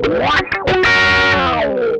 MANIC GLISS1.wav